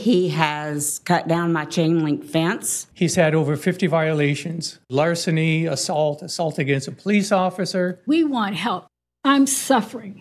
Several of them took their complaints to the city council last night.